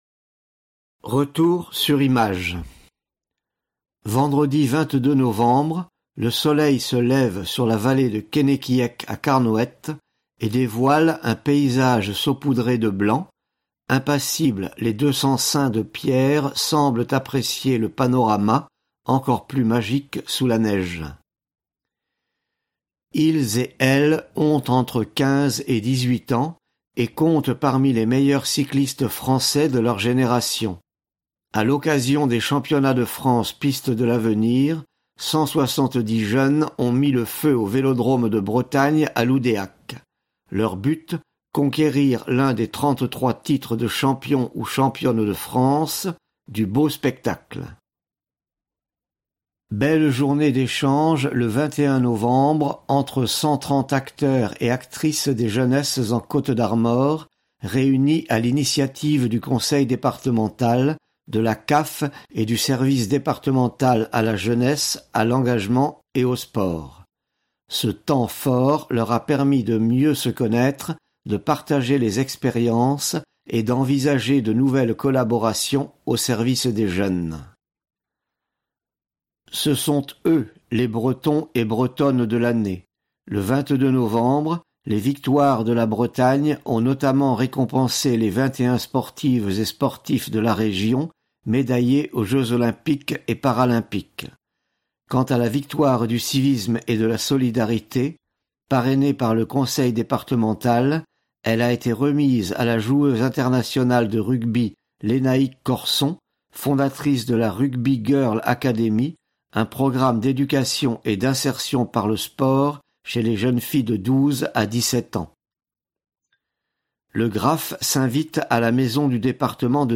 Découvrez le magazine 200, mis en voix grâce au gracieux concours des Bibliothèques sonores de France.